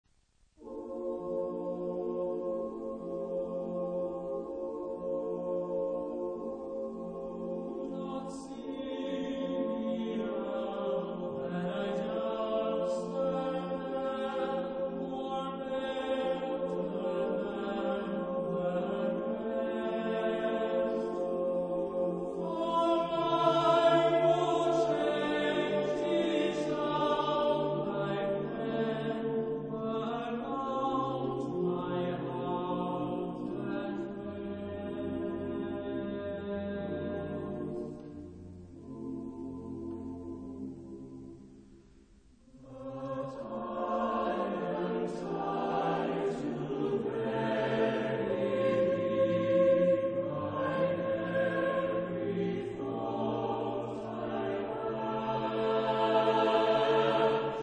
Epoque: 20th century
Type of Choir: SATB  (4 mixed voices )